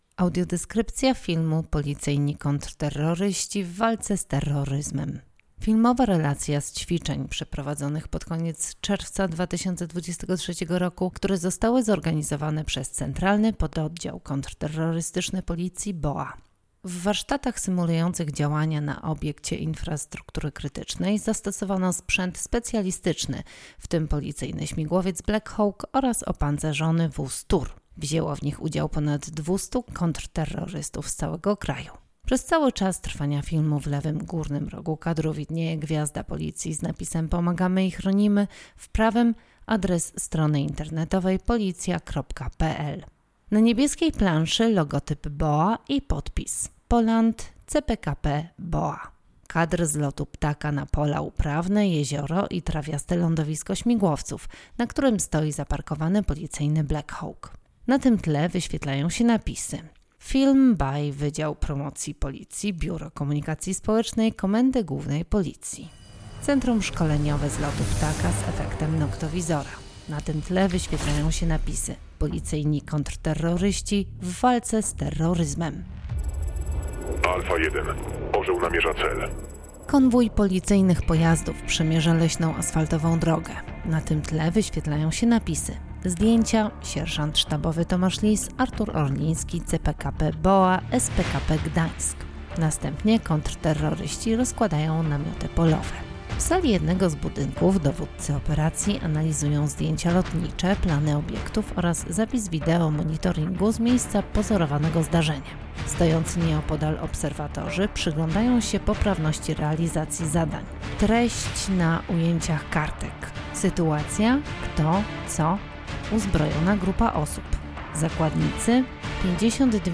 Nagranie audio Audiodeskrypcja do filmu: Policyjni kontrterroryści w walce z terroryzmem - ogólnopolskie ćwiczenia